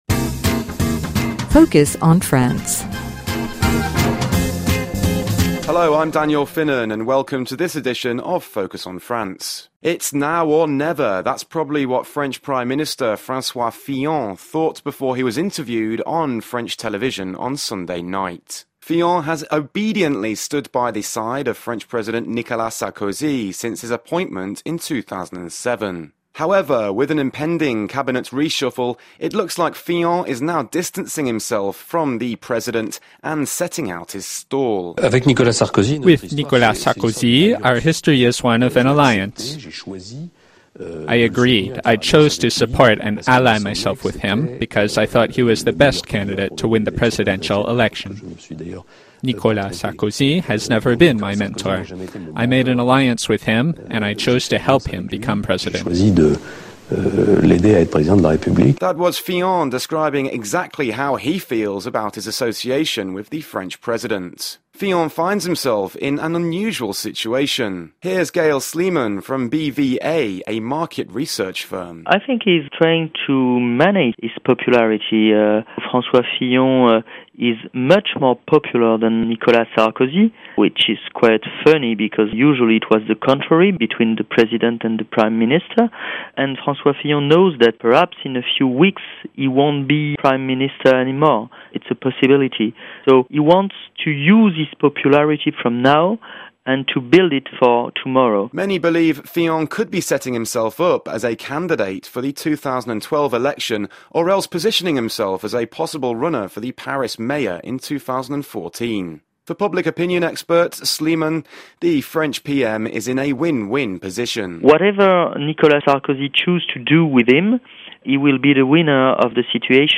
Radio Feature: Focus on France